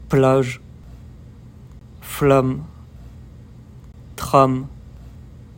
Click on the button to listen to how the sound [a] is pronounced in words plage, flamme, tram.